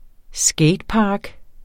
Udtale [ ˈsgεjdˌpɑːg ]